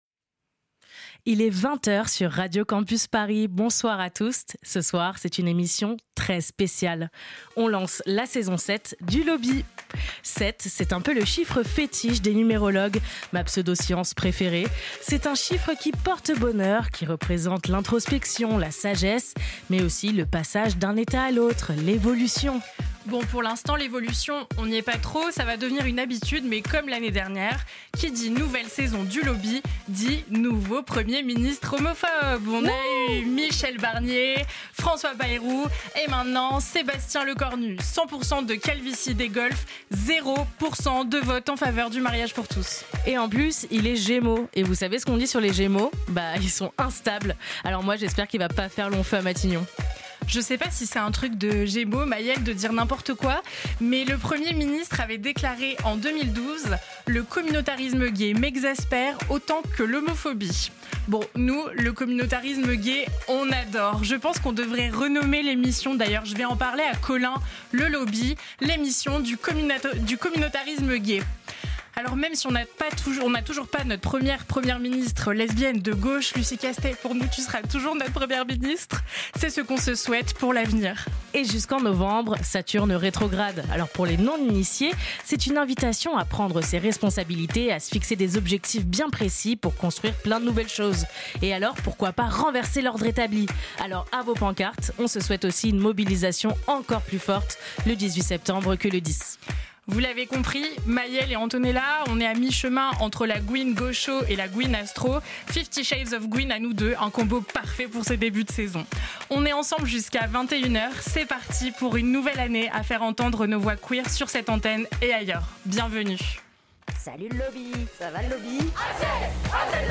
Programmation musicale
Pour fêter la 7e saison du Lobby, on reçoit un groupe qu’il va falloir suivre de très près.